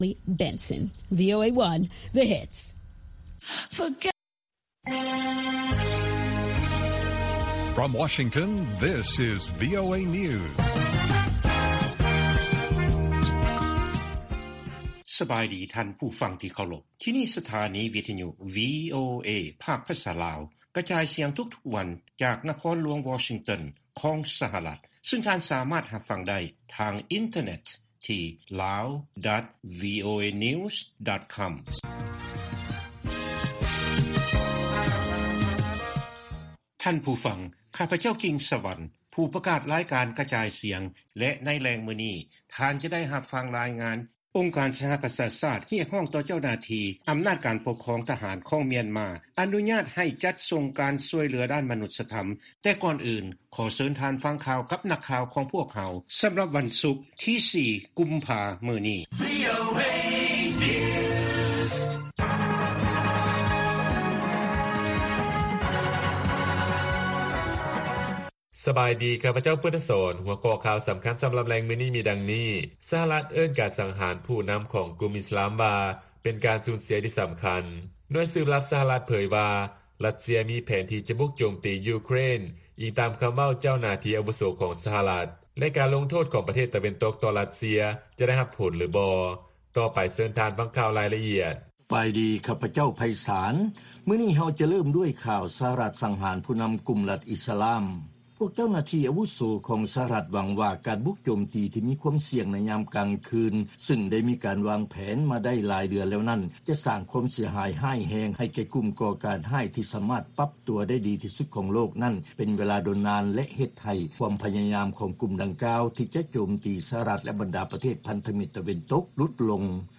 ລາຍການກະຈາຍສຽງຂອງວີໂອເອ ລາວ: ສະຫະລັດເອີ້ນການສັງຫານຜູ້ນຳຂອງກຸ່ມລັດອິສລາມວ່າ ‘ເປັນການສູນເສຍທີ່ສຳຄັນ